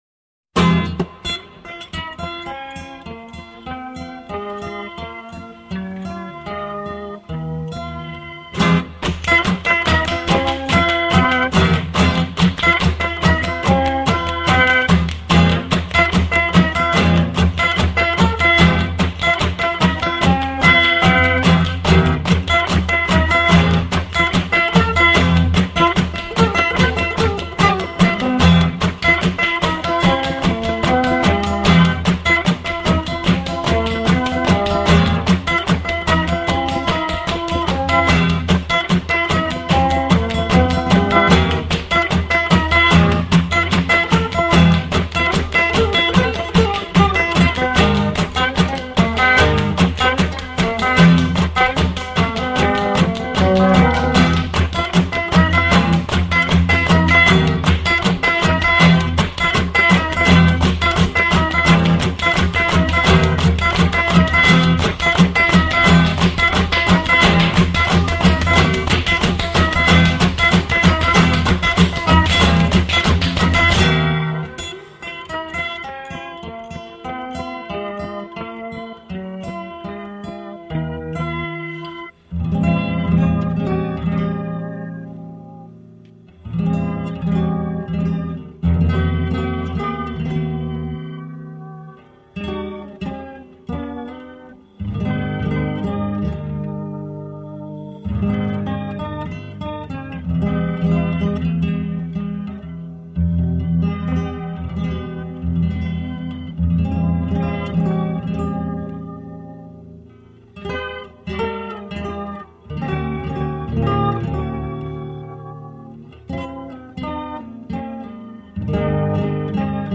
Спокойная лиричная работа в блюзовом ключе.